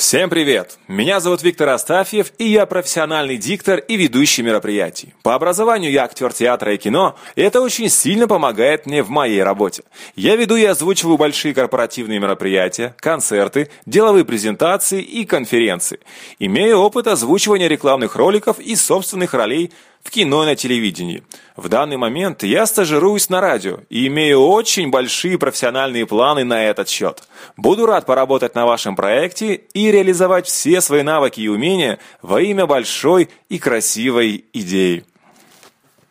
Диктор - Визитка.mp3
Мужской